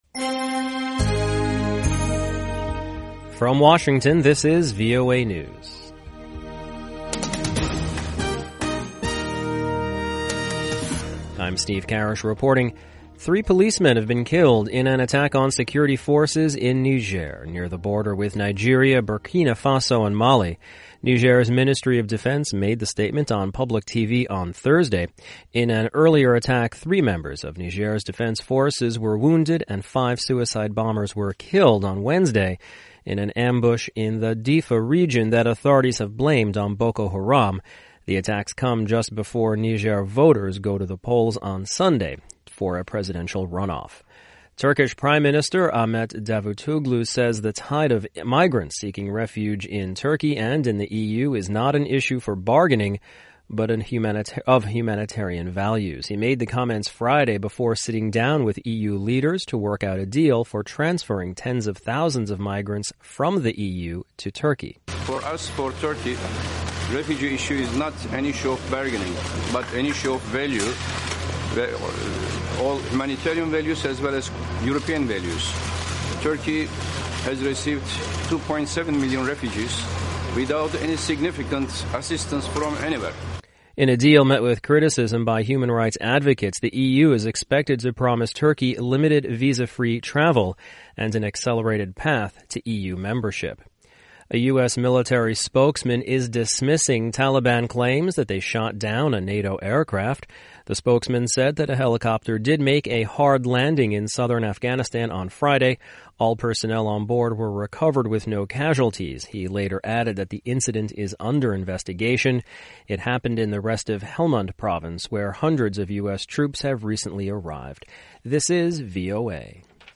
VOA English Newscast: 1400 UTC March 18, 2016